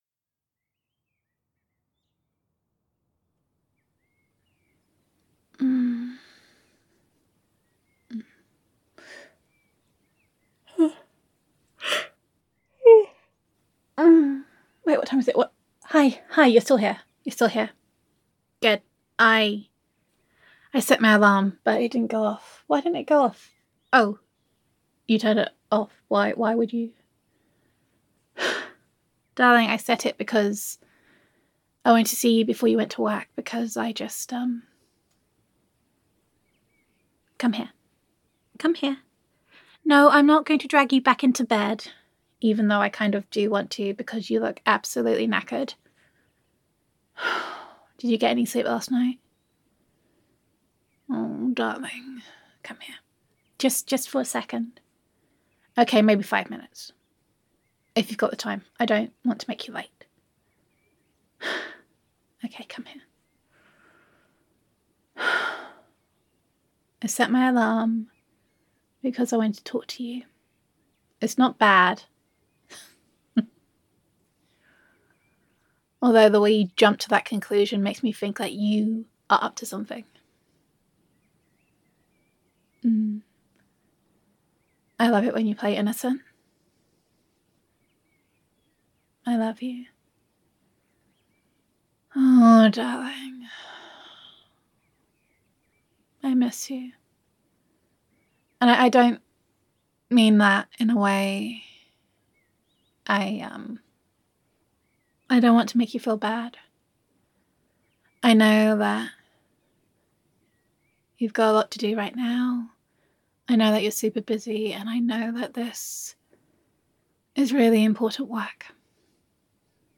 [F4A] Morning Sweetness for a Key Worker [Tough Times][Girlfriend Roleplay][Appreciation][Loving][Lockdown][Gender Neutral][Appreciation Before a Hard Day at Work]